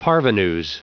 Prononciation du mot parvenus en anglais (fichier audio)
Prononciation du mot : parvenus